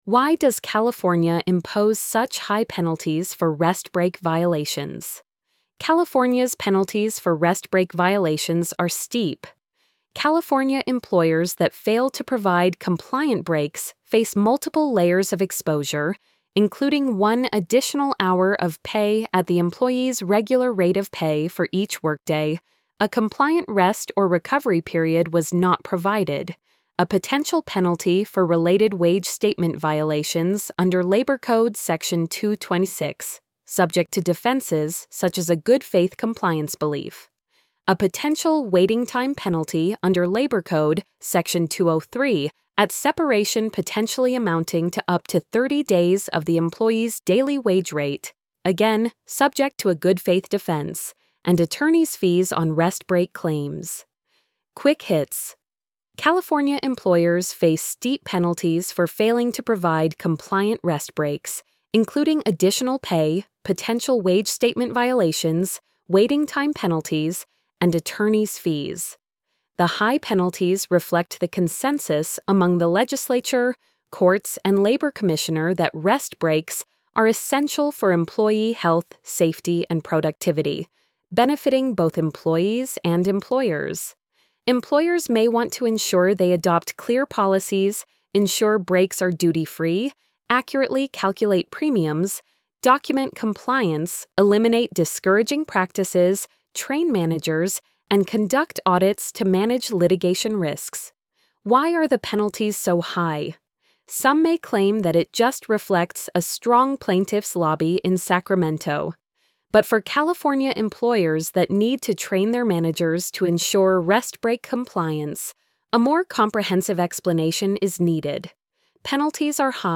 why-does-california-impose-such-high-penalties-for-rest-break-violations-tts.mp3